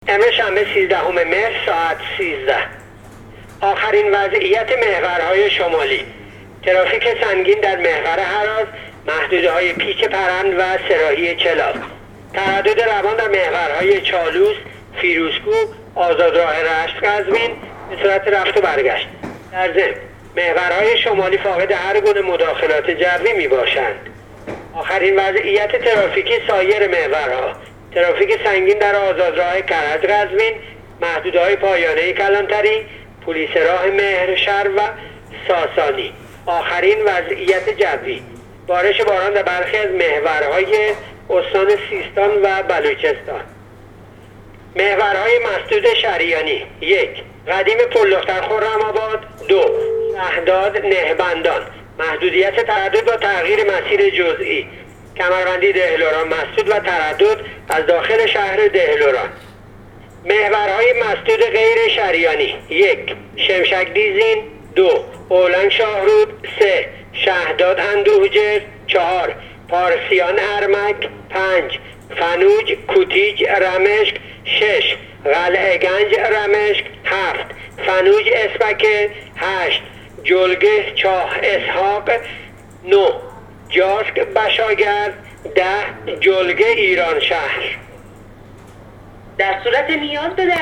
گزارش رادیو اینترنتی پایگاه خبری وزارت راه و شهرسازی از آخرین وضعیت ترافیکی جاده‌های کشور تا ساعت ۱۳سیزدهم مهرماه/ترافیک نیمه سنگین و سنگین در محور هراز و آزادراه کرج - قزوین